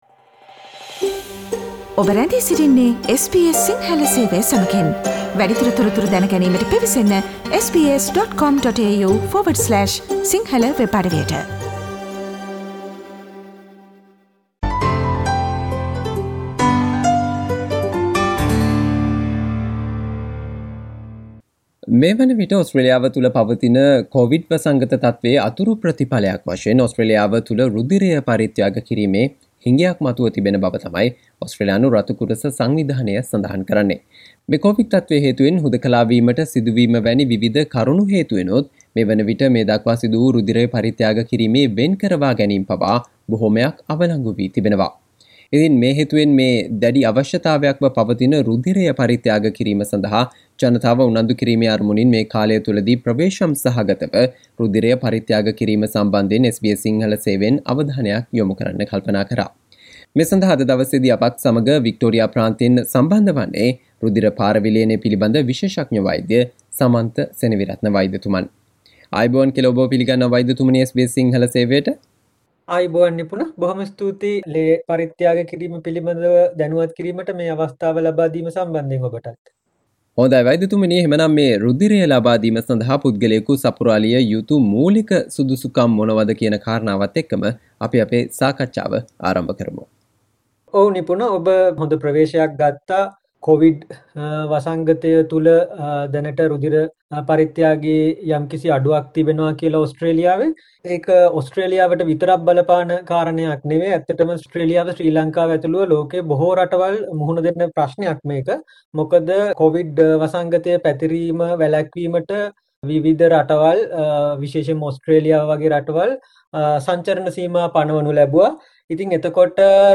කෝවිඩ් තත්වයත් සමග ඔස්ට්‍රේලියාව තුල රුධිරය පරිත්‍යාග කිරීමේ හිඟයක් මතුව තිබීම හේතුවෙන් රුධිරය පරිත්‍යාග කිරීමට ජනතාව උනන්දු කිරීමේ අරමුණින් SBS සිංහල සේවය සිදු කල සාකච්චාවට සවන්දෙන්න